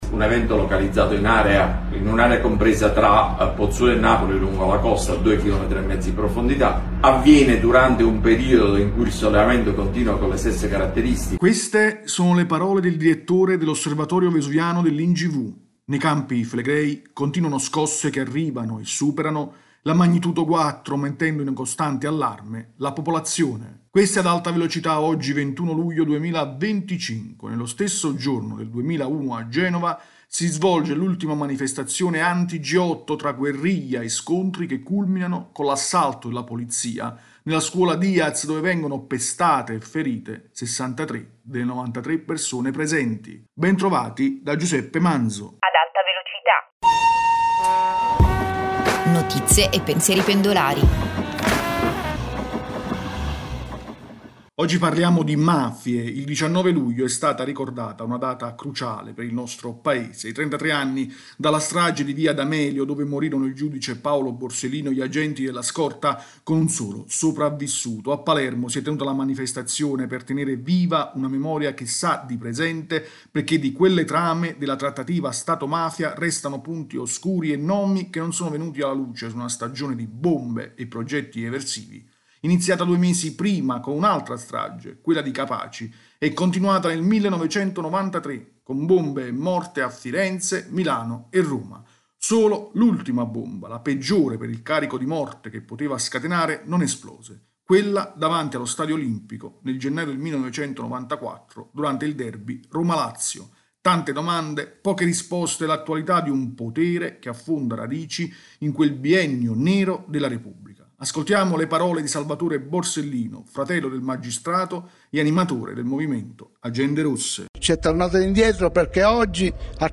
Ascoltiamo le parole di Salvatore Borsellino, fratello del magistrato e animatore del movimento Agende Rosse.
rubrica quotidiana